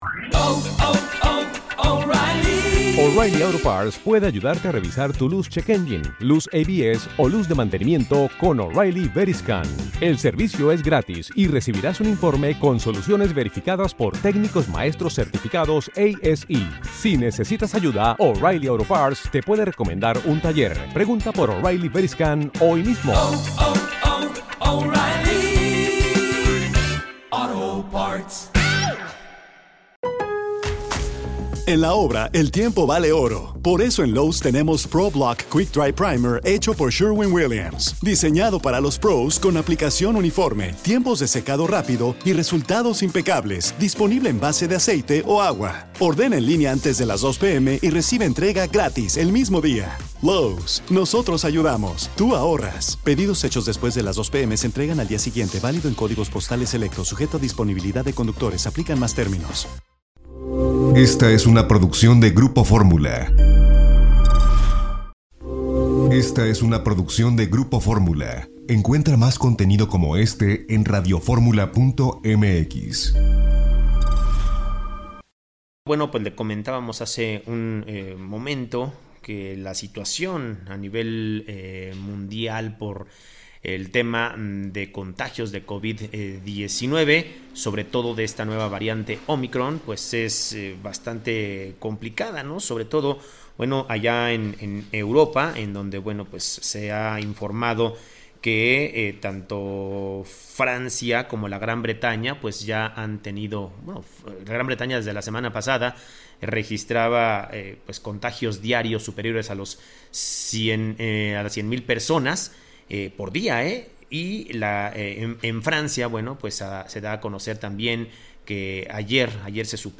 Miguel Barbosa, gobernador de Puebla, habla sobre desigualdad, COVID y el Va por México - 16.12.2021